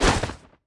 Media:sunburn_barbarian_atk_03.wav 攻击音效 atk 局内攻击音效
Sunburn_barbarian_atk_02.wav